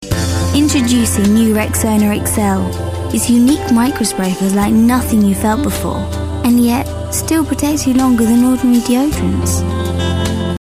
Straight
Commercial, Young, Sexy, Smooth